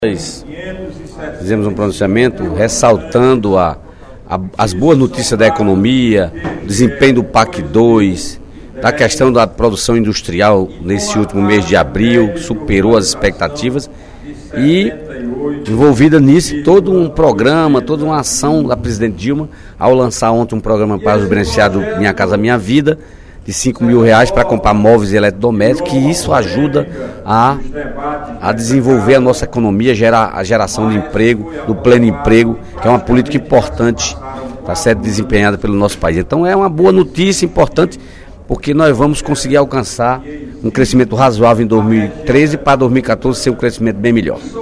O deputado Dedé Teixeira (PT) destacou, durante o segundo expediente da sessão plenária desta quarta-feira (12/06), as políticas de educação profissional iniciadas no governo Lula e continuadas no governo de Dilma Rousseff.